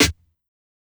SNARE_BROKEN.wav